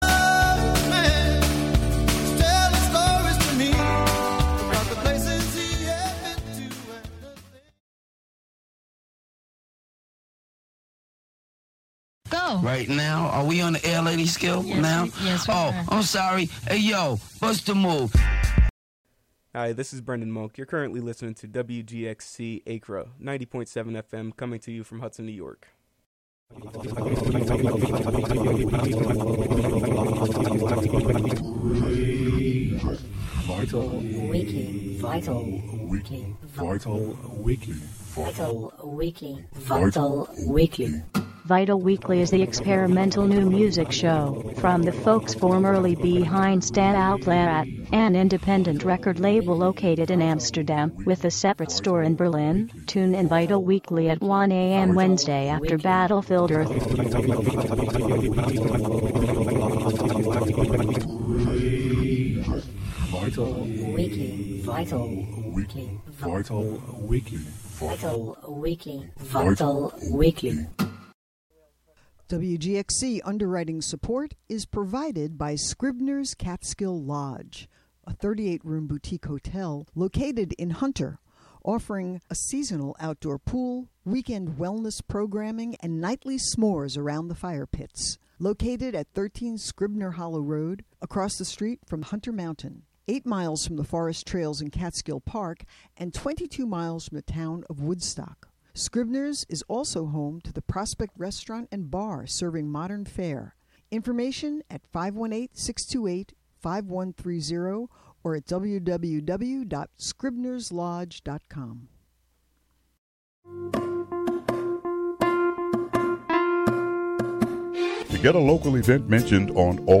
"All Together Now!" is a daily news show brought to you by WGXC-FM in Greene and Columbia counties. The show is a unique, community-based collaboration between listeners and programmers, both on-air and off. "All Together Now!" features local and regional news, weather updates, feature segments, and newsmaker interviews.